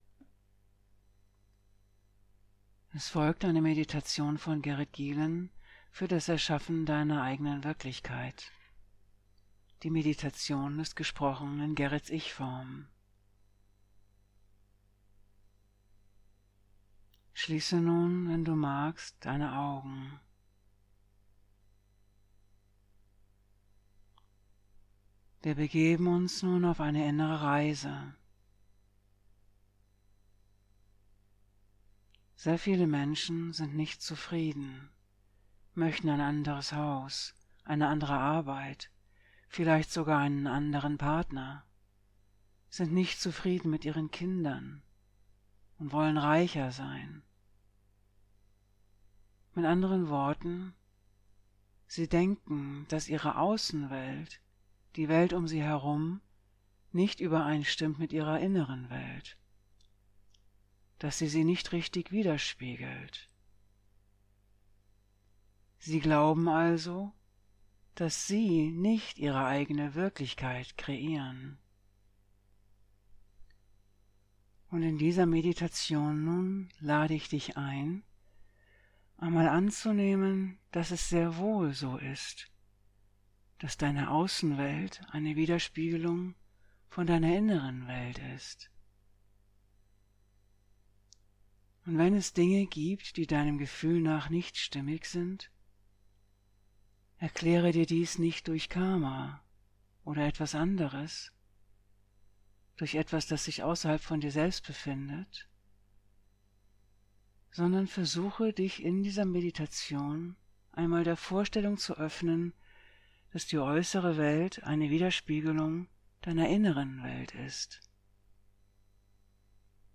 EURE EIGENE WIRKLICHKEIT ERSCHAFFEN Einleitung und Meditation (Audio)